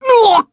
Roman-Vox_Damage_jp_03.wav